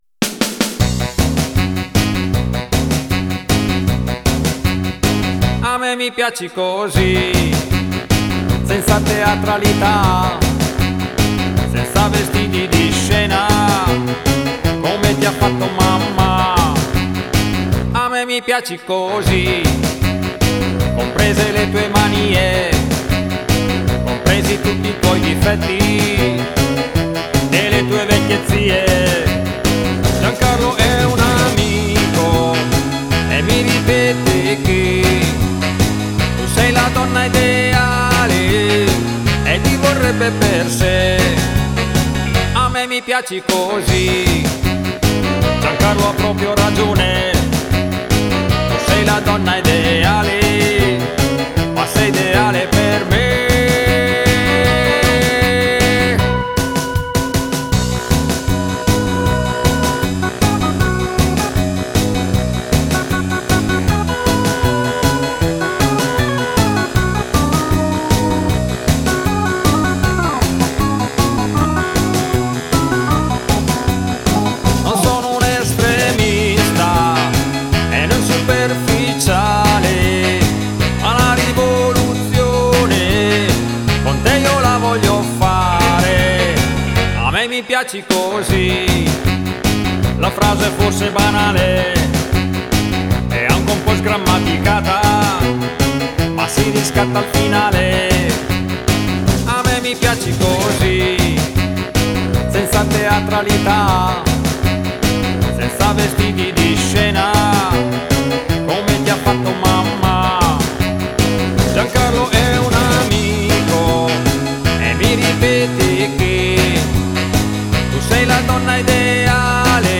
stroll